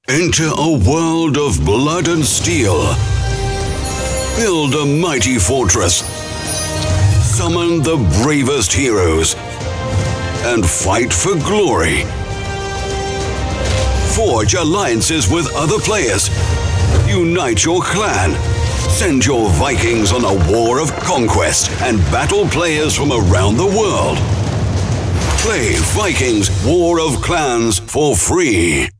Native speakers
Engels (vk)